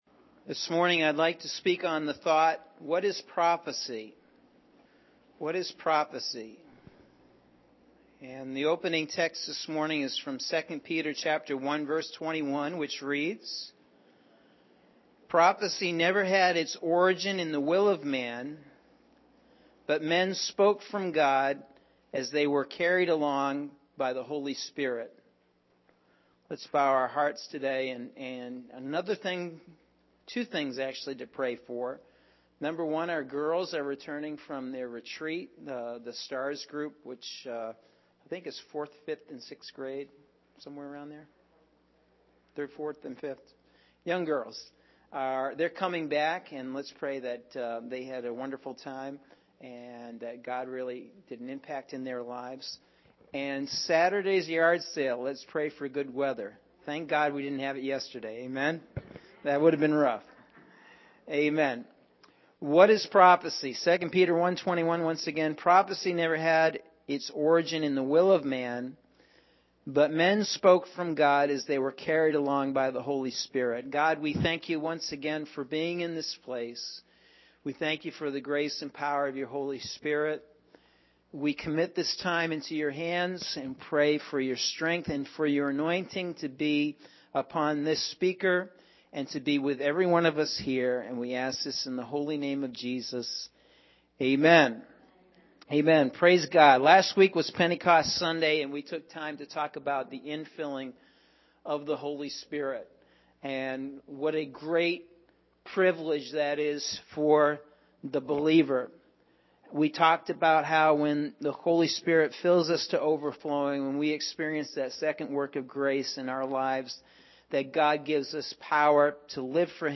Sunday June 3rd – AM Sermon – Norwich Assembly of God